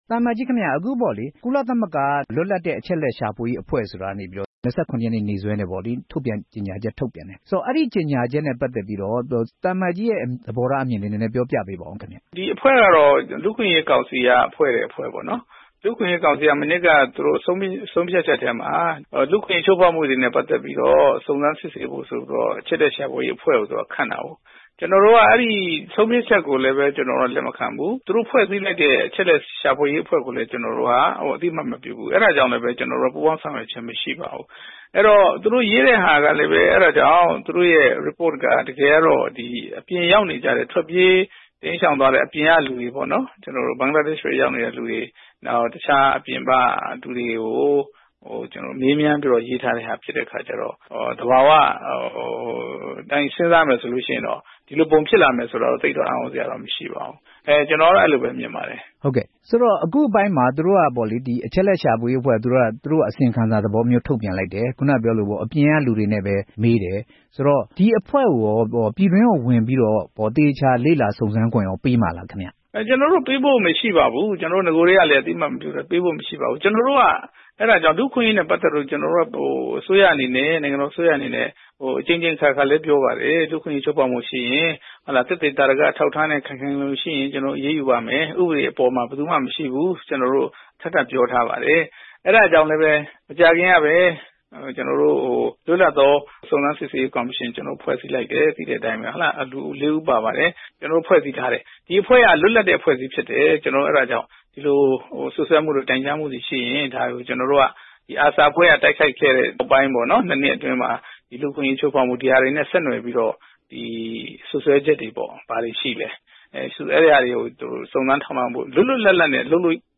ကုလသမဂ္ဂ အချက်အလက် ရှာဖွေရေးအဖွဲ့ ထုတ်ပြန်တဲ့ အစီရင်ခံစာ အကြောင်း မေးမြန်းချက်